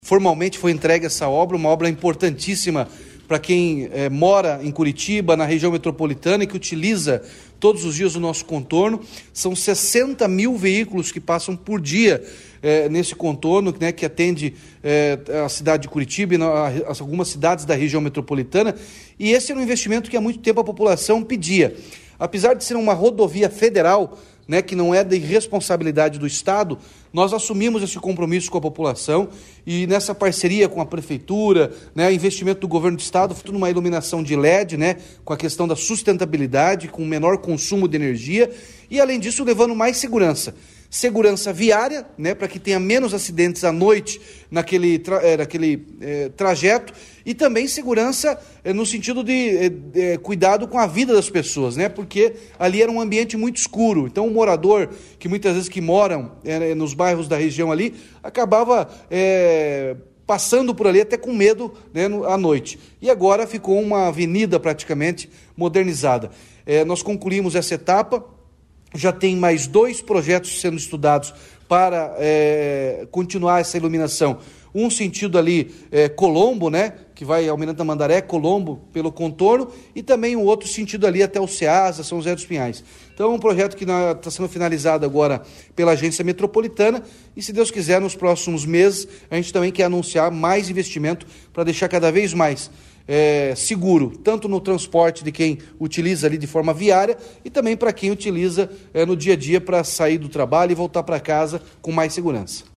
Sonora do governador Ratinho Junior sobre a conclusão das obras de iluminação do Contorno Sul de Curitiba